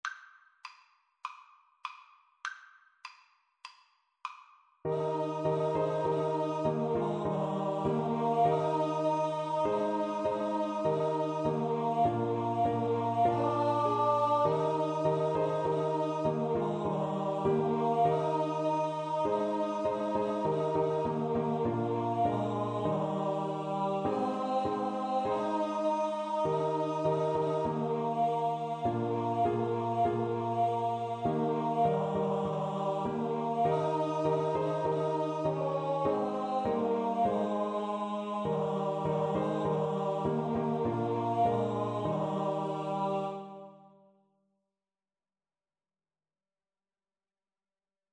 Free Sheet music for Choir (SATB)
4/4 (View more 4/4 Music)
Classical (View more Classical Choir Music)